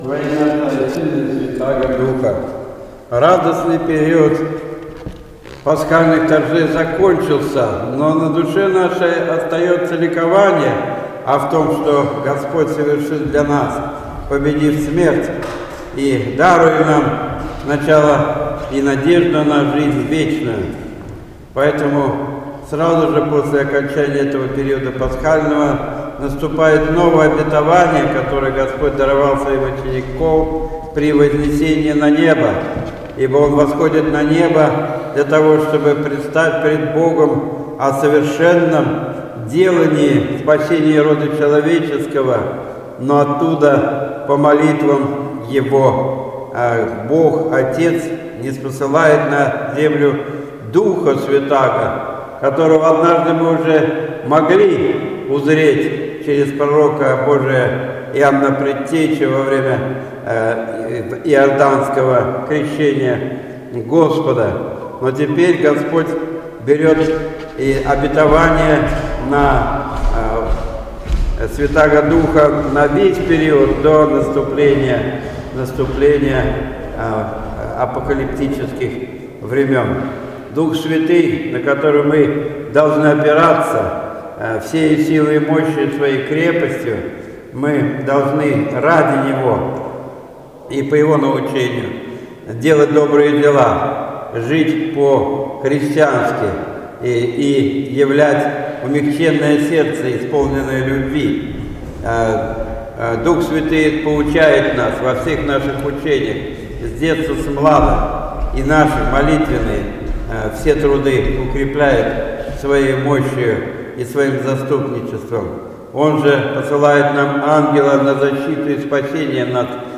День 28 мая, в предпразднество Вознесения Господня, так и называется: Отдание праздника Пасхи. Слово